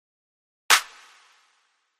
Free Foley sound effect: Single Clap.
Single Clap
089_single_clap.mp3